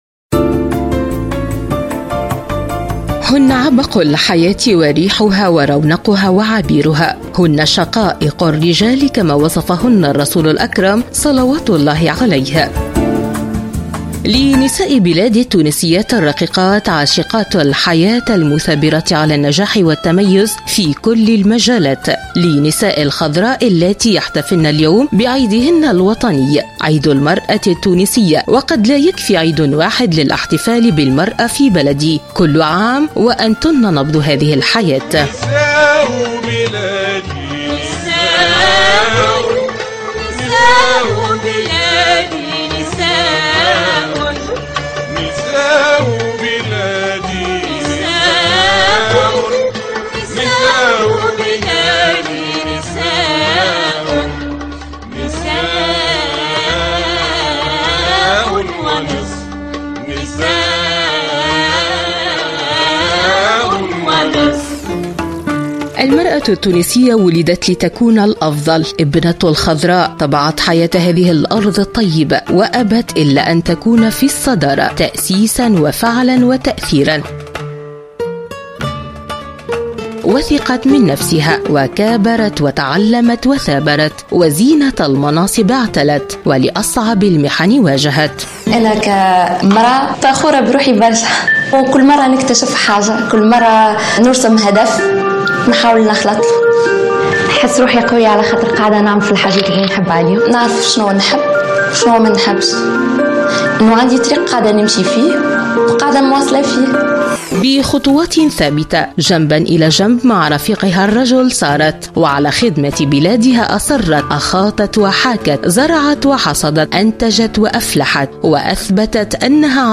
تقرير